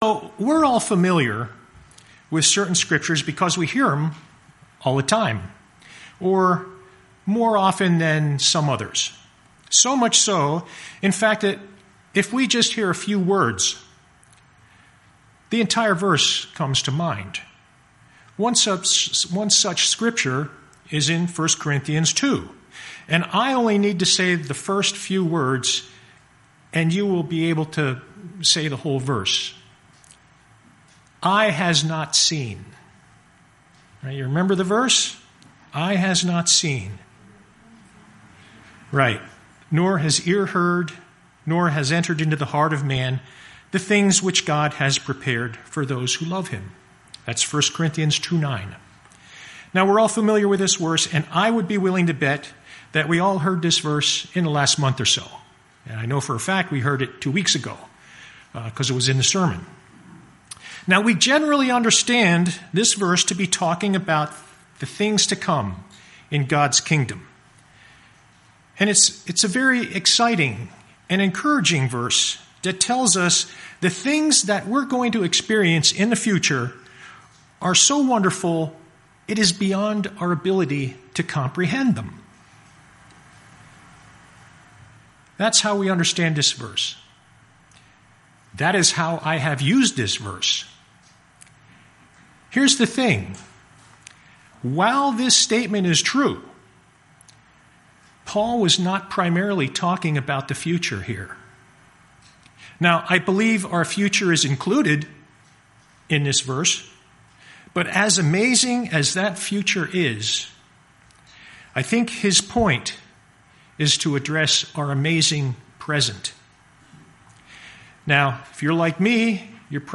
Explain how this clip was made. Given in Lehigh Valley, PA